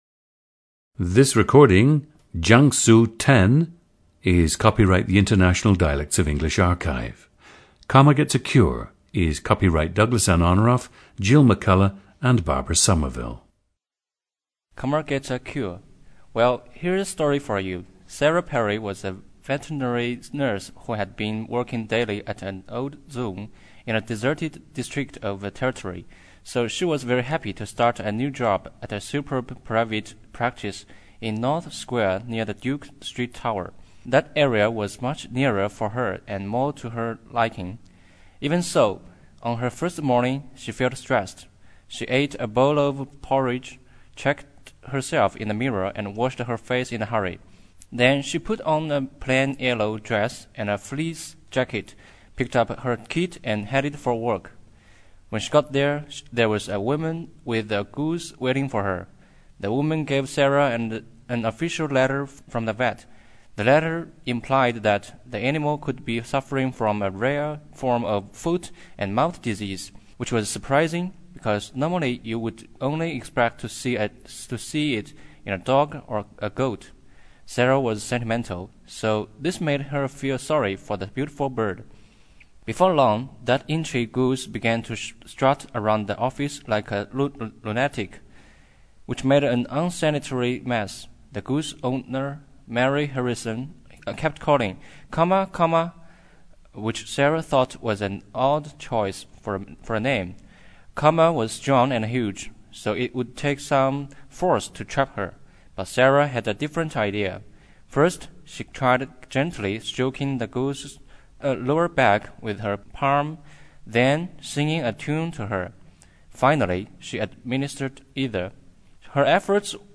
GENDER: male
ETHNICITY: Han Chinese
ORTHOGRAPHIC TRANSCRIPTION OF UNSCRIPTED SPEECH:
The subject now goes on to read the following abstracts from the Analects of Confucius in his own Taixinghua dialect.